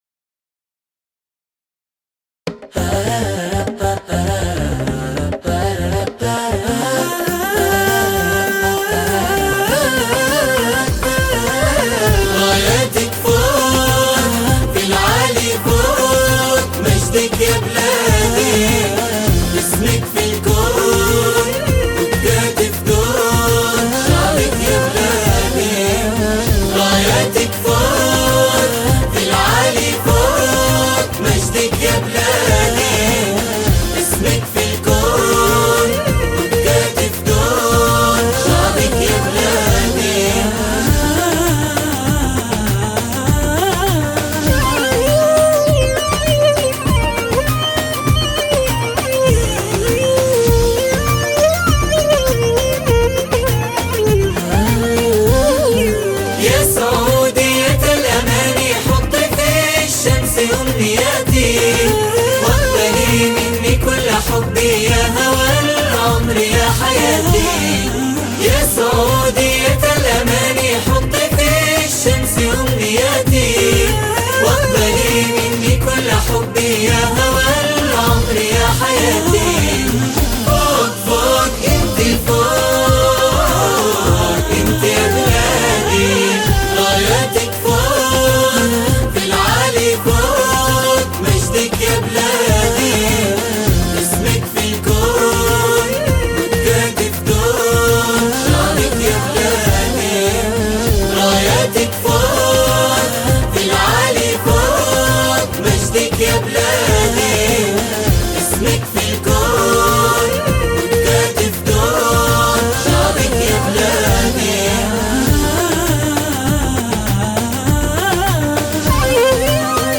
أنشودة عن الوطن لليوم الوطني بدون موسيقى